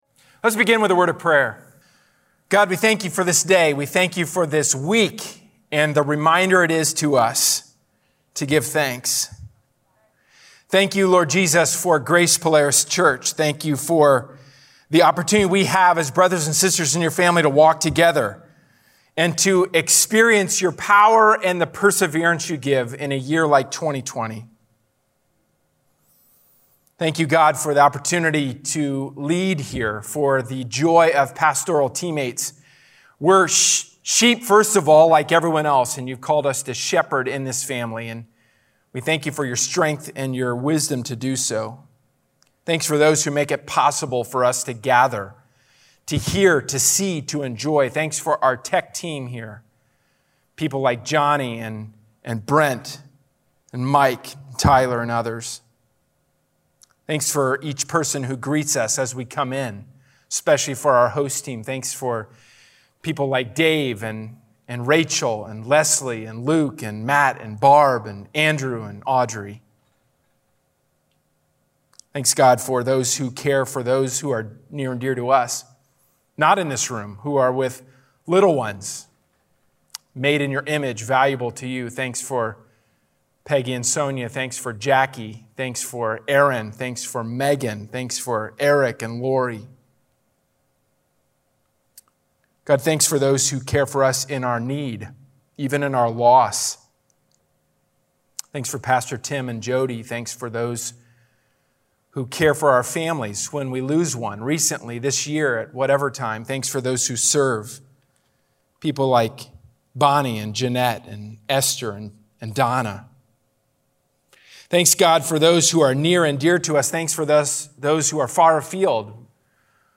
A sermon from the series "Living Out the Gospel." The transformed life is portrayed in the pattern of our relationships.